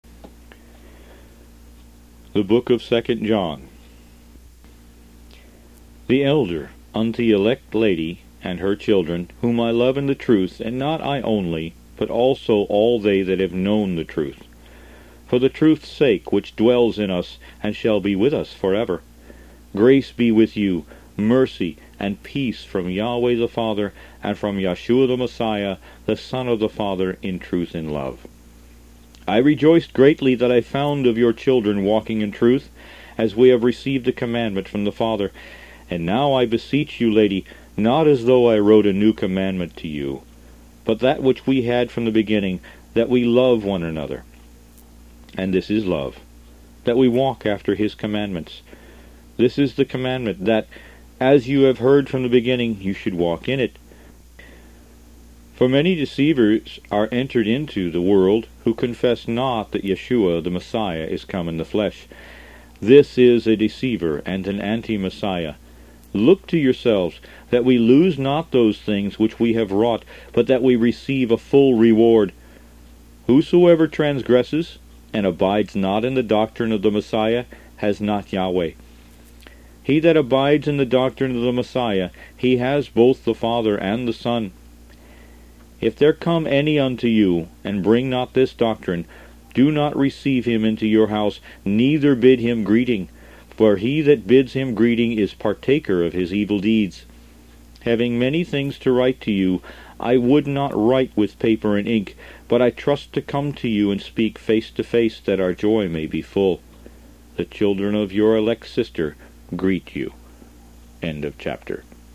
•--> LOOKUP CURRENT-TIME SCRIPTURE <--• Root > BOOKS > Biblical (Books) > Audio Bibles > Messianic Bible - Audiobook > 24 The Book Of 2nd John File name Size Last changed .. 14 2nd John CH 1.mp3 1.71 MB 06.07.08 23:22:36